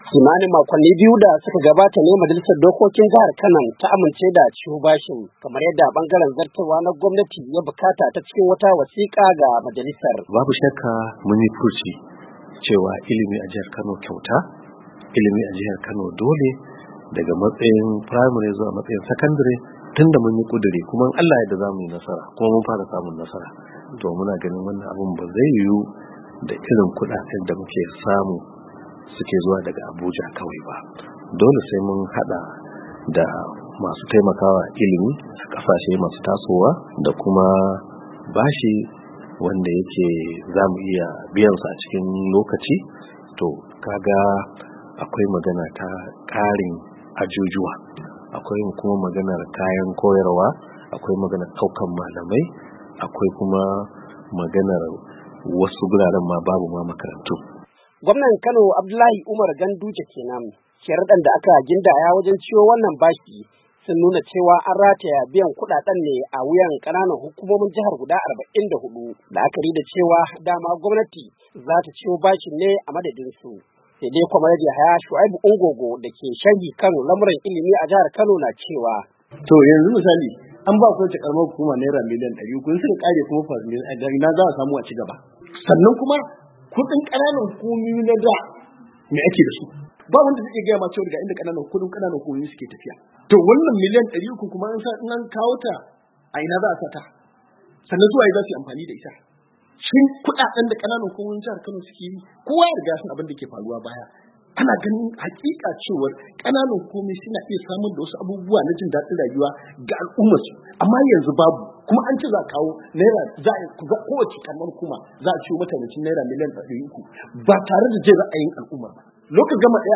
A saurari rahoto cikin sauti daga jihar Kano a Najeriya.